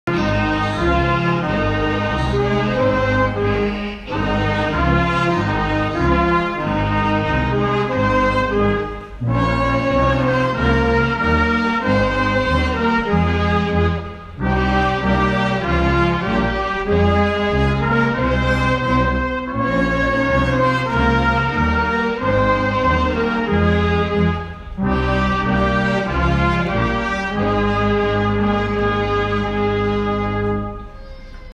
Koncert kolęd pod oknami
To dlatego do dzieci leczących się w Zespole Szpitali Miejskich w Chorzowie przyszła dzisiaj Orkiestra Dęta Sanktuarium Św. Floriana w Chorzowie.
Kilkunastu artystów zagrało najpiękniejsze polskie kolędy oraz pastorałki i świąteczne hity. Z bezpiecznej odległości liczonej w… piętrach, wtórowali im mali pacjenci.
Kolędy-3.m4a